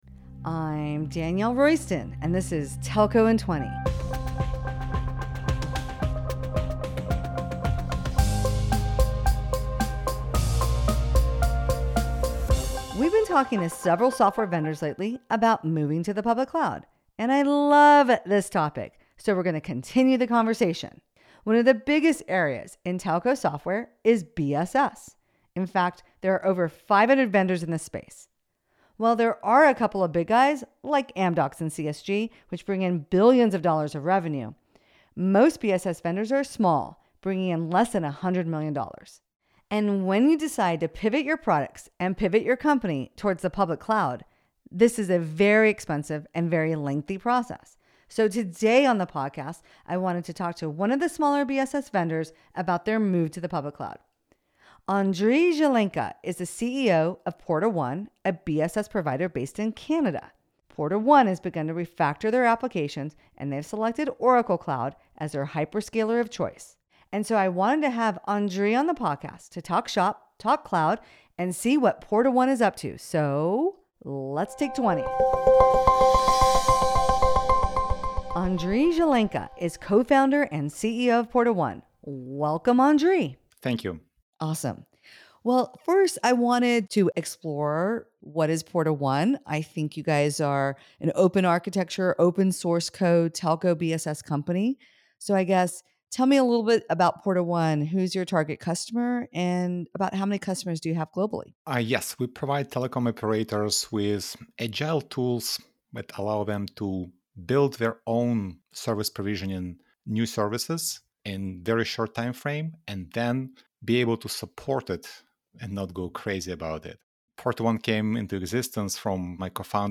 Hit the play button to hear our conversation about: